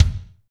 Index of /90_sSampleCDs/Northstar - Drumscapes Roland/DRM_Fast Shuffle/KIK_F_S Kicks x